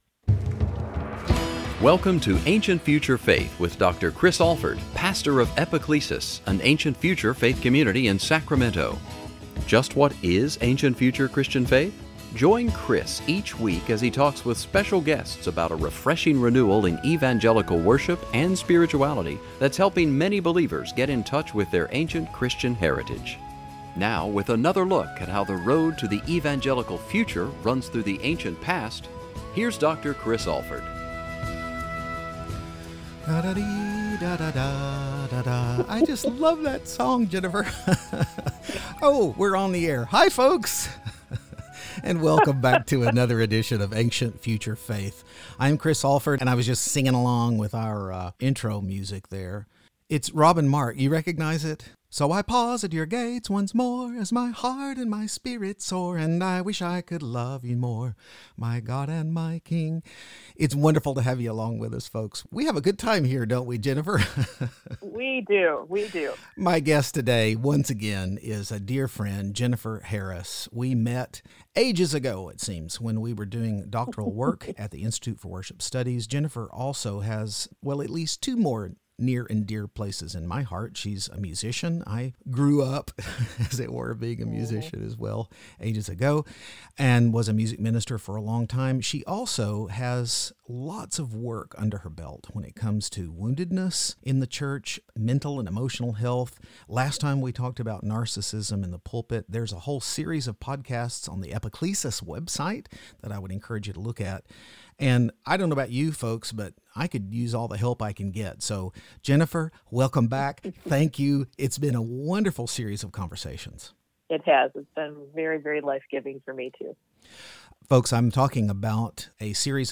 What happens when narcissism comes to church, and what should we do about folks in the church who exhibit its traits? Join us for the second in three important interviews about the destructive nature of this difficult-to-diagnose disorder: Part 1, Narcissism in the Pulpit; Part 2, Narcissism in the Pew; Part 3, Narcissism in the Parish.